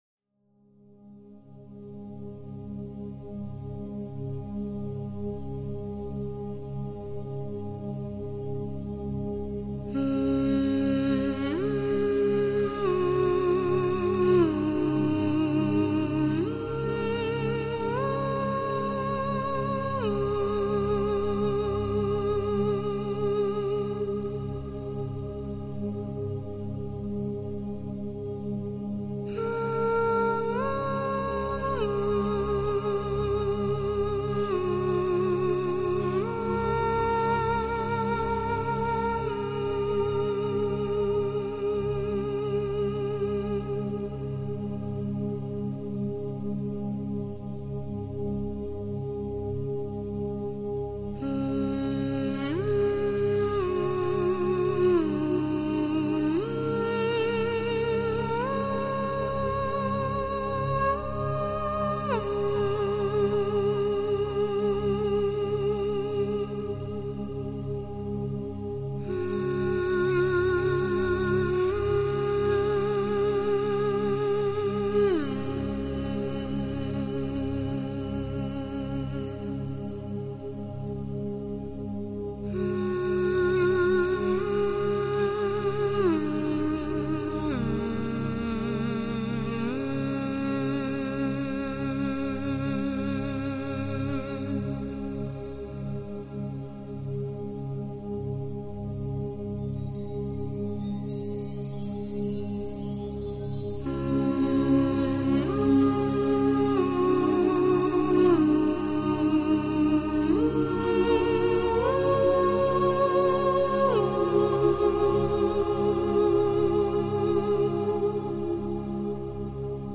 Жанр: New Age ?